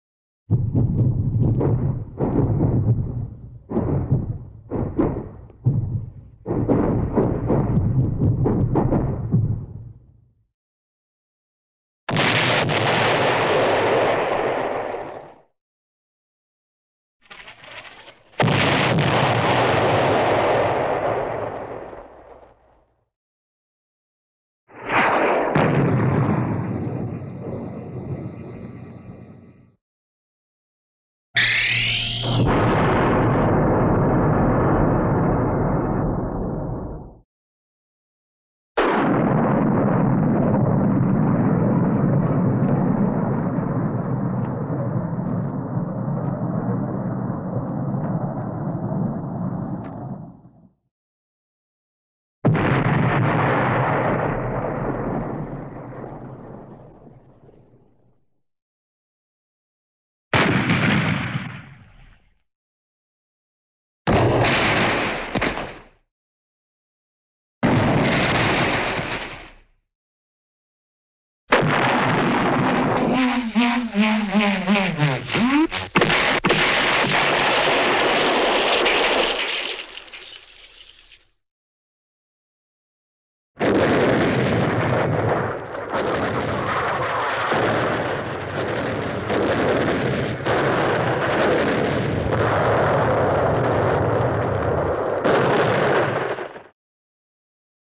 40 1 Explosion Distant Artillery Explosions
Category: Sound FX   Right: Personal
Tags: Cartoon